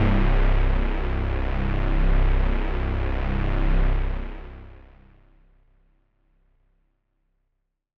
Lush Pad 2 C2.wav